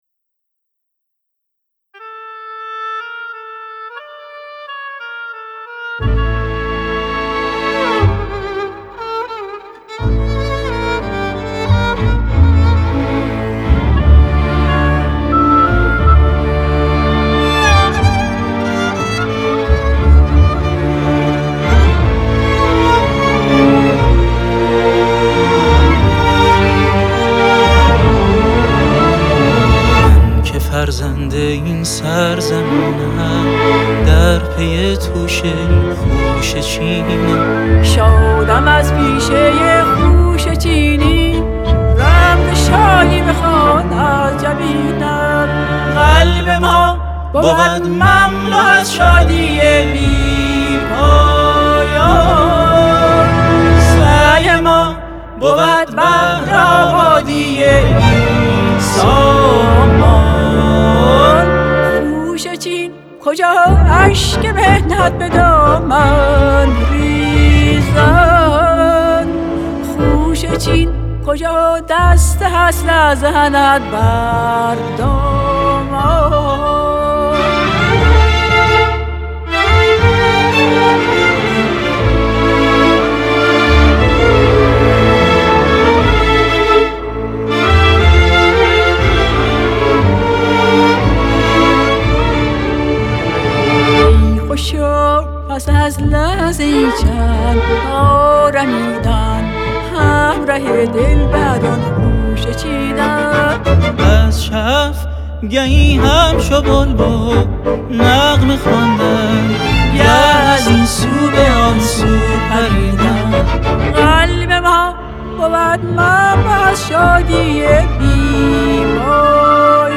ویولن‌ ، ویولن‌ آلتو‌ ، سولو ویولن
کنتر باس
استودیو مدرن